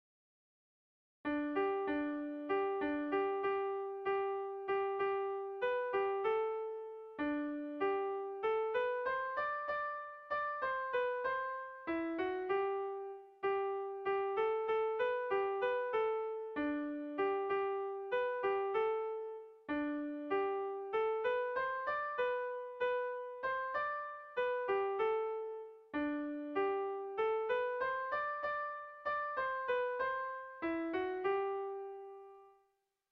Bertso melodies - View details   To know more about this section
Erlijiozkoa